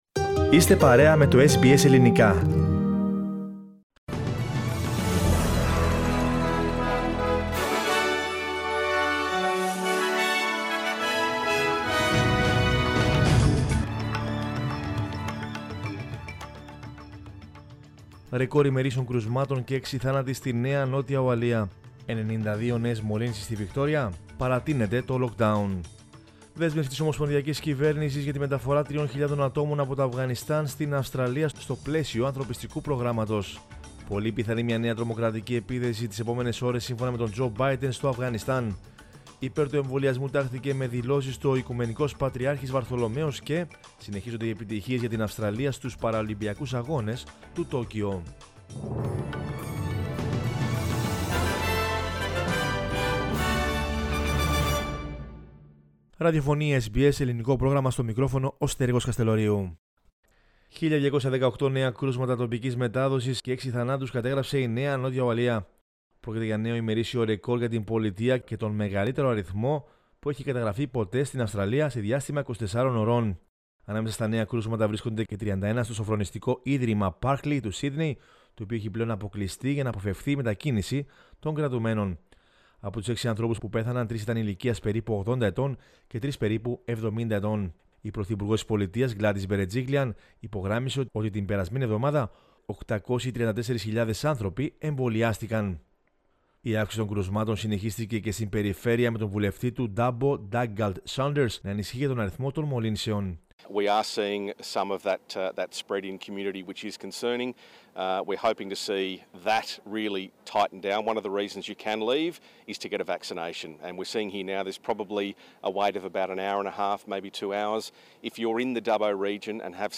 News in Greek from Australia, Greece, Cyprus and the world is the news bulletin of Sunday 29 August 2021.